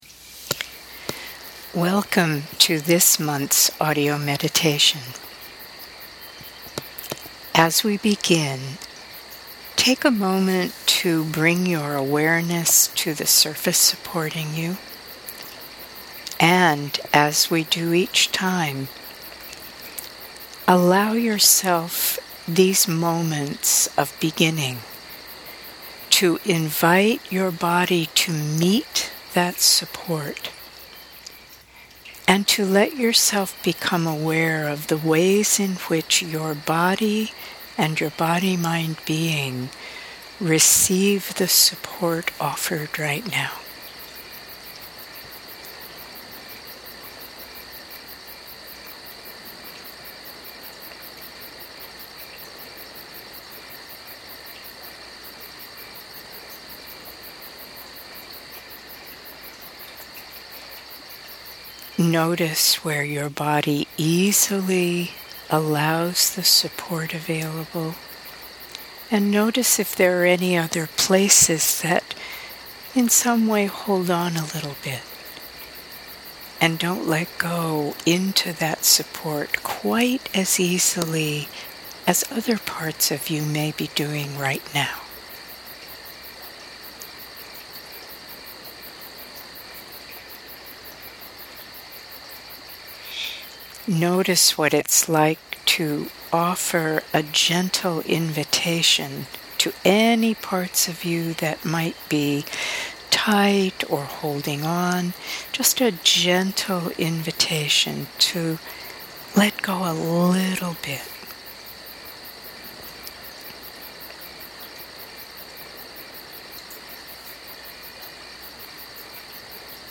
Here’s the audio version of the meditation, with music: